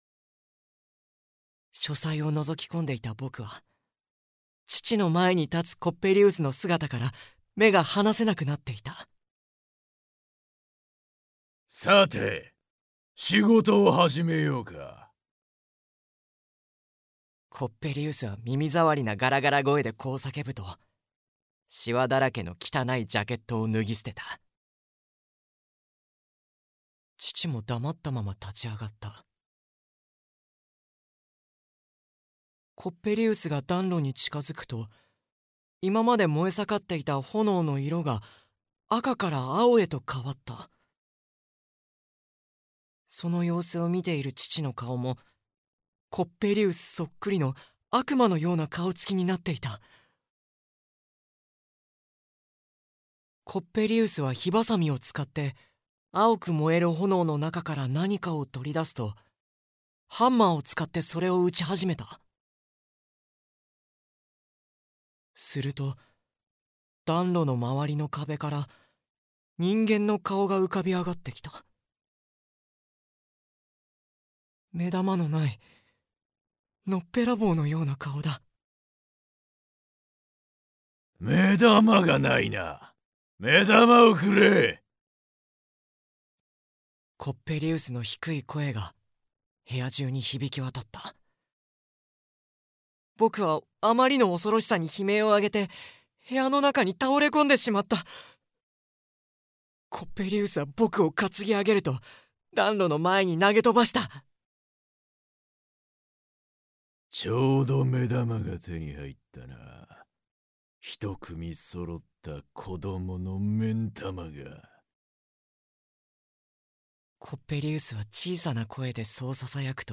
[オーディオブック] 砂男（こどものための聴く名作46）